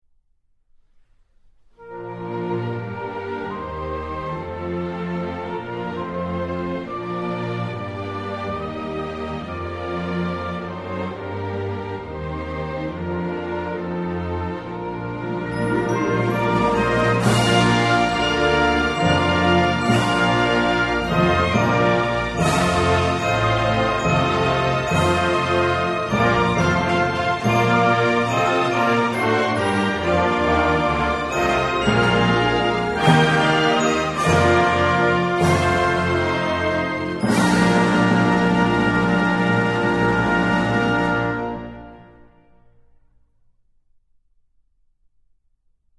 Inno nazionale Inghilterra.mp3